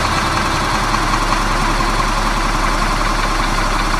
Bus Engine
A city bus engine idling and pulling away with diesel rumble and air brake hiss
bus-engine.mp3